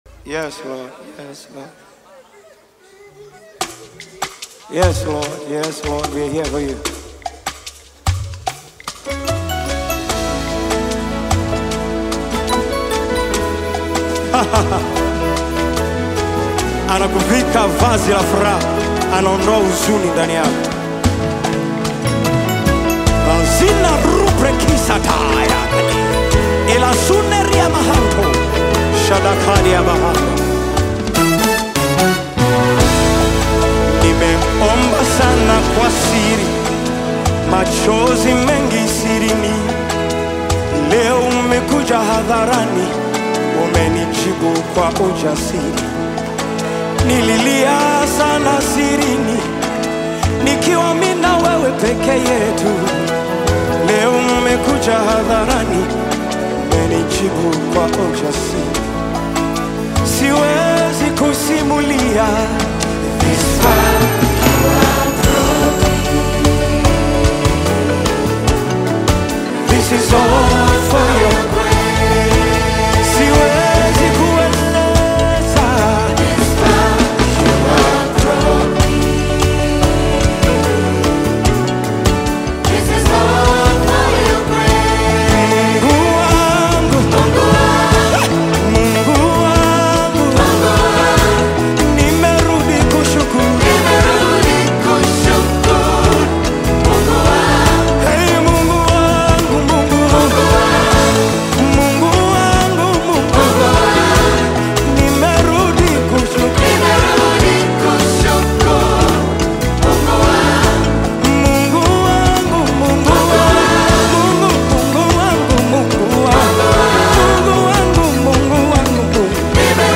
Praise Gospel music track